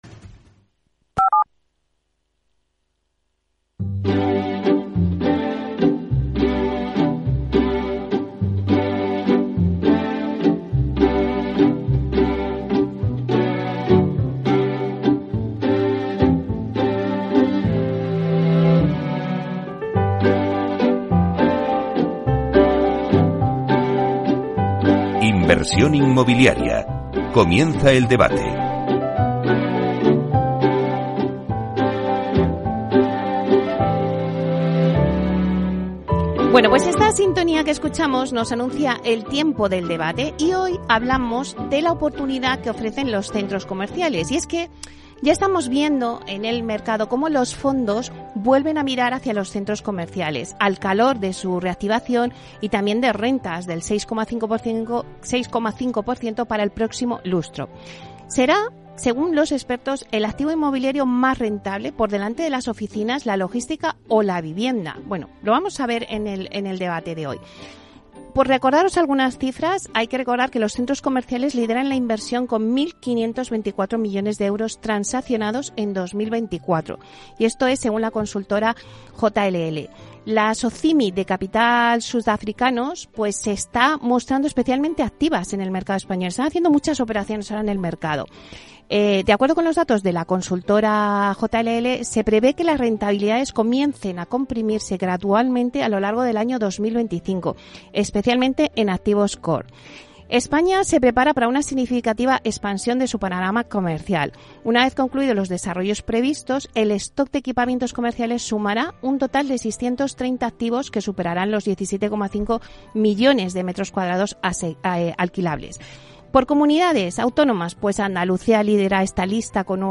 Inversión Inmobiliaria ofrece las claves para que el inversor realice la mejor operación. Le tomamos el pulso al sector con la noticia inmobiliaria de la semana, análisis de mercado y un debate con la actualidad del sector para buscar oportunidades de negocio con nuestros mejores expertos inmobiliarios.